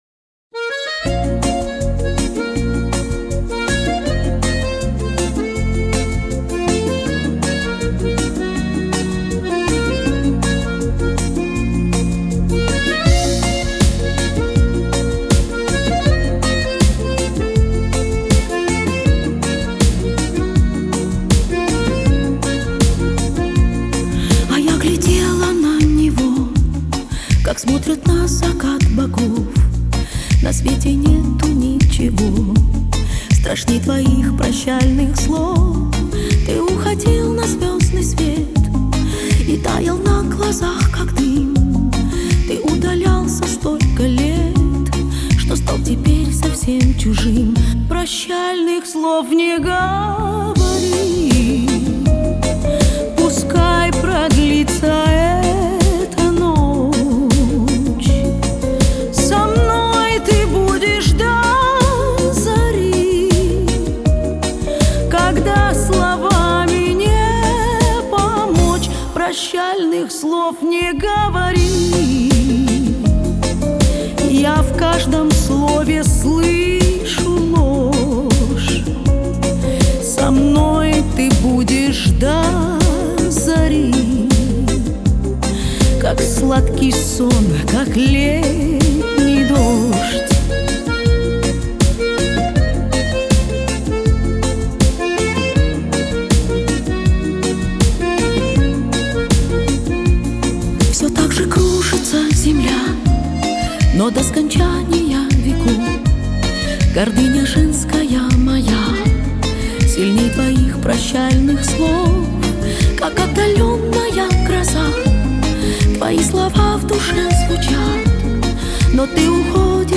Genre: Pop
Style: Ballad, Schlager, Vocal